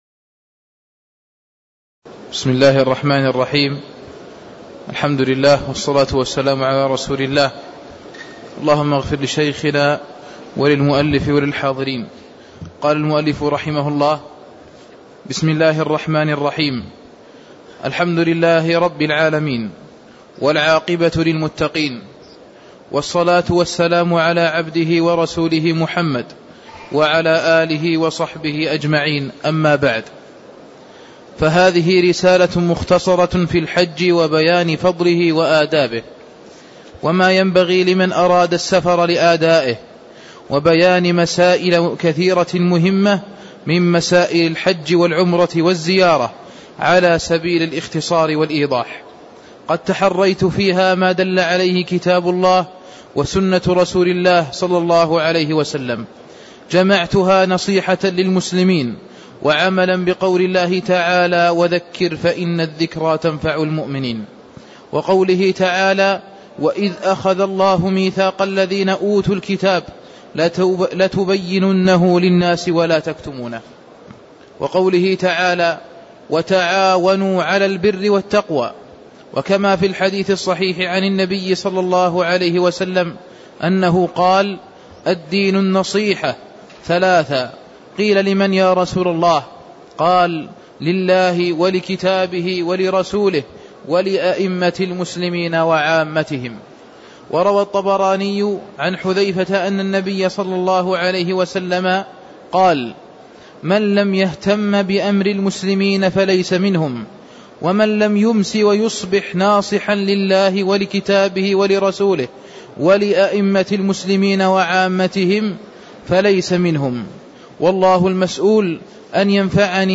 تاريخ النشر ١٤ ذو القعدة ١٤٣٦ هـ المكان: المسجد النبوي الشيخ: فضيلة الشيخ عبدالله بن محمد آل خنين فضيلة الشيخ عبدالله بن محمد آل خنين المقدمة (01)